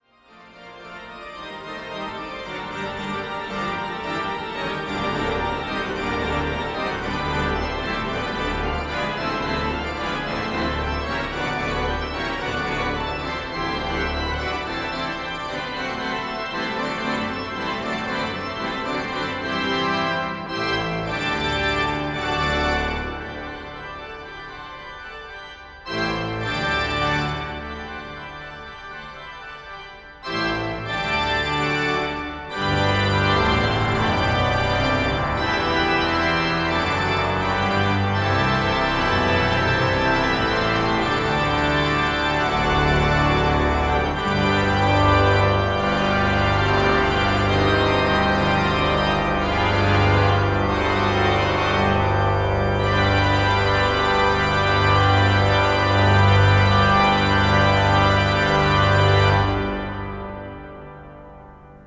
Auf diese Klingel kann ich MP3's in fast beliebiger Menge und Laufzeit aufspielen - klar, dass es eine Orgelmusik wird...
- meine zweit-Lieblings-Toccata...
Es darf schon mit einer lauten Passage beginnen, aber ich werde die MP3 so gestalten, dass die Lautstärke "aufgeht".